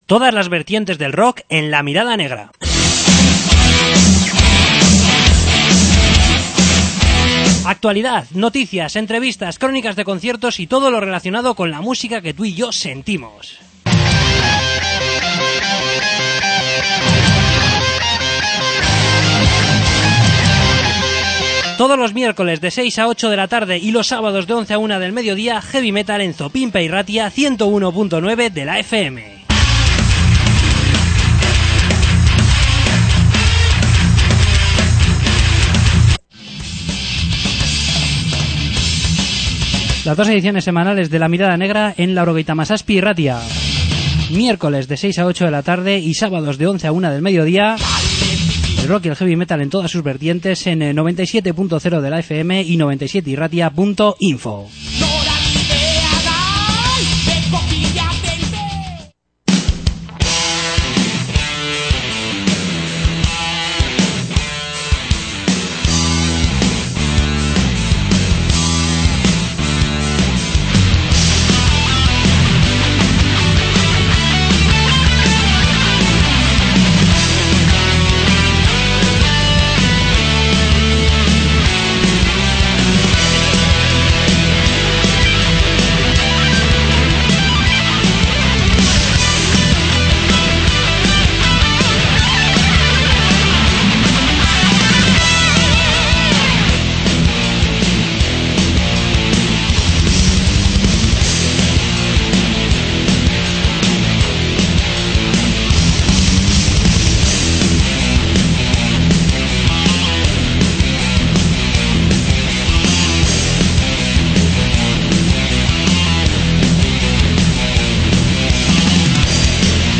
Entrevista con Sealed Room